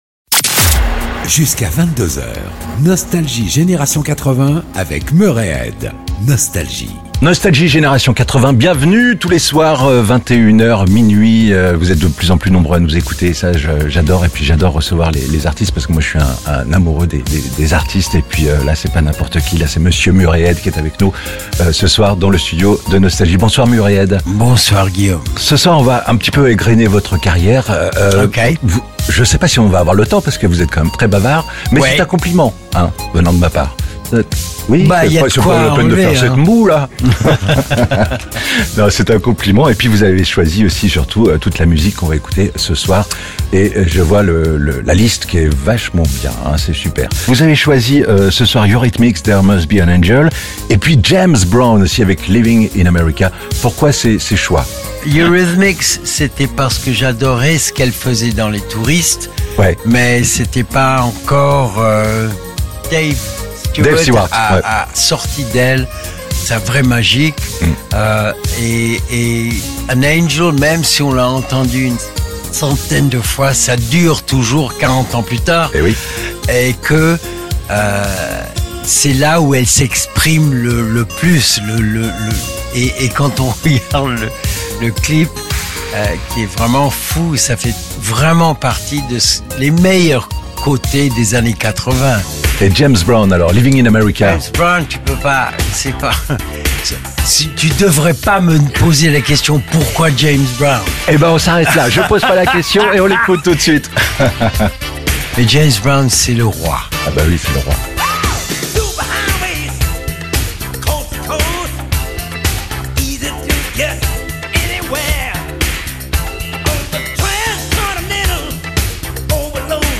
Le plus frenchy des chanteurs et acteurs british, Murray Head, était l'invité de NOSTALGIE GENERATION 80 pour choisir la musique qu'il aime et partager les rencontres qui ont jalonné sa vie.